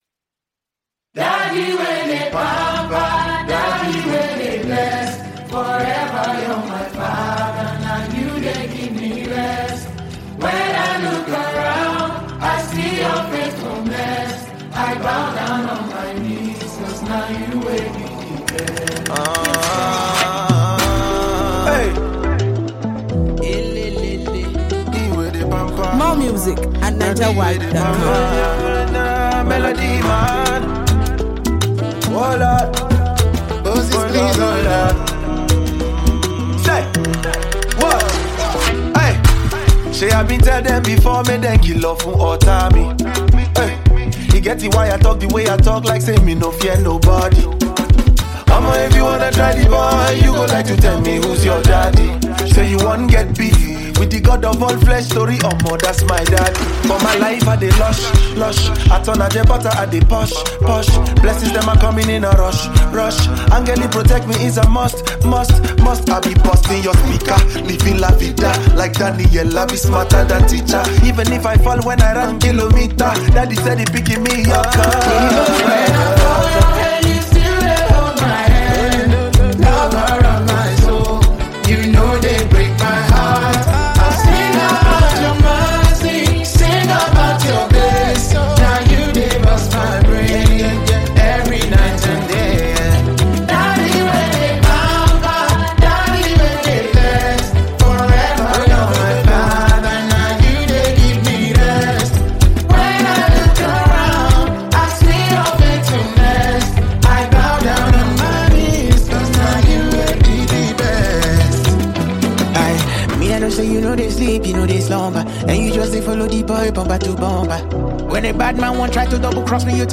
a thrilling Afrobeats collaboration